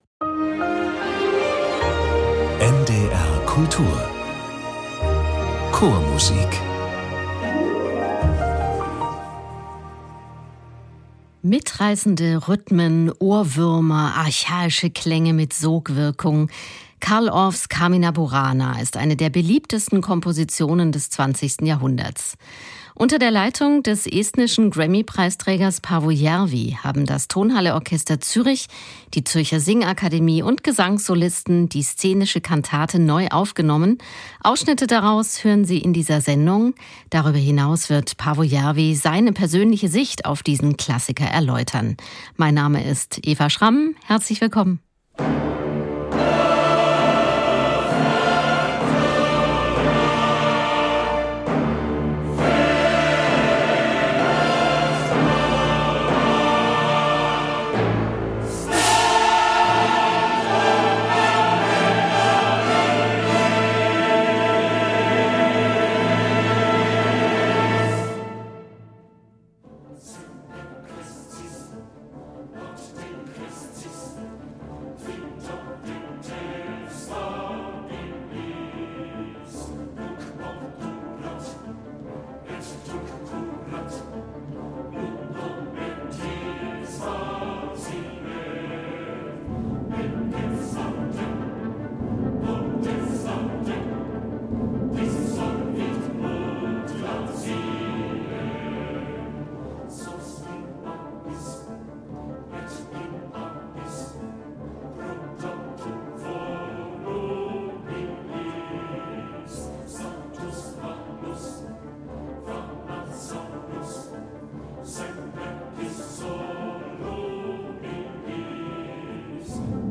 Ausschnitte aus dem Klassiker in einer neuen Aufnahme